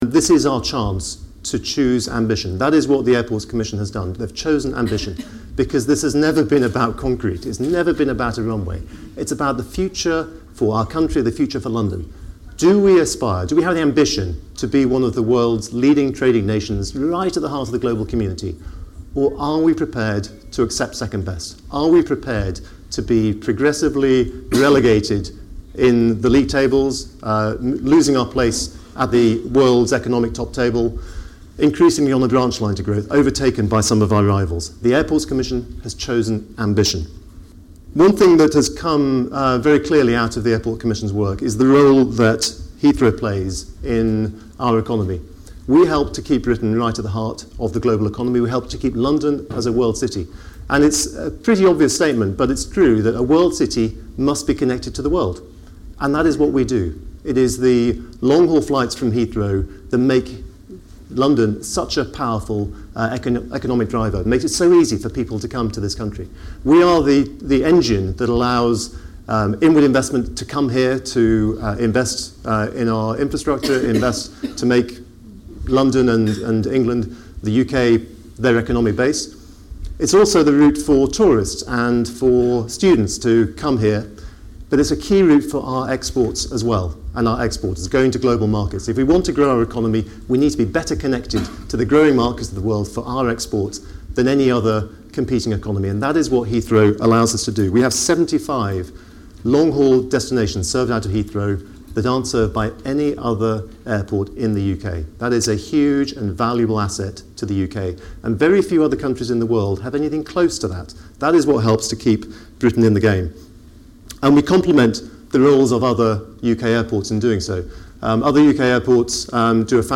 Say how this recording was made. He also tells business leaders at the London First / LCCI event why he believes politicians will get behind plans for a third runway at Heathrow. The event was hosted by CBRE.